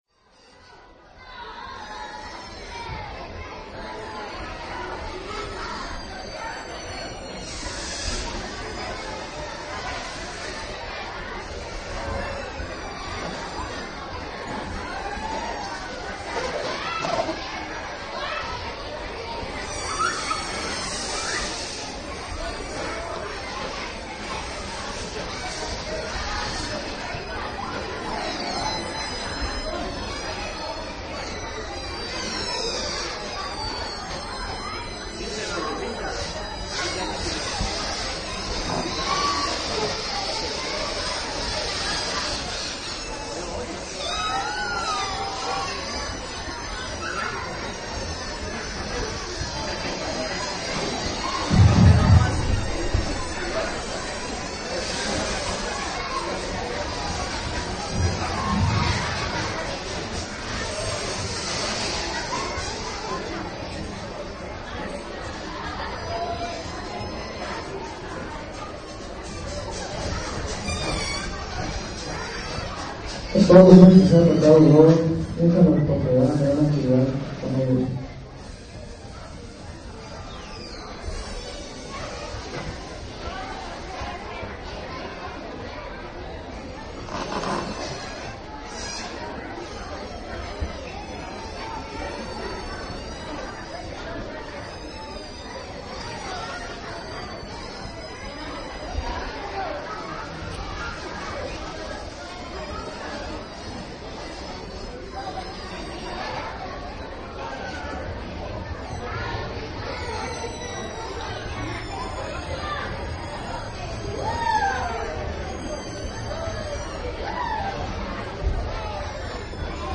220 niños
220 niños esperando se les entregan globos añoras el silencio
Lugar: Auditorio del Centro Cultural de Chiapas Jaime Sabines en Tuxtla Gutierrez Equipo: Minidisc Sony MZ-N707 ,micrófono de construcción casera (más info)